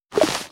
ItemPickUp.wav